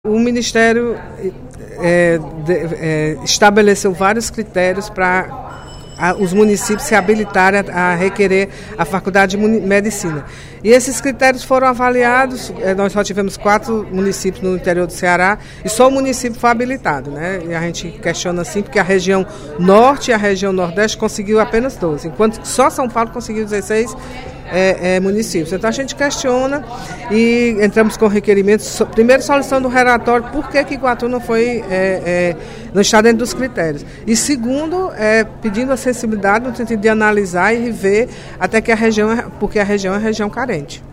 A deputada Mirian Sobreira (Pros) criticou, no primeiro expediente da sessão plenária da Assembleia Legislativa desta sexta-feira (06/11), a forma como foram apontados, pelo Ministério da Educação, os locais para implantação de novos cursos de medicina no País.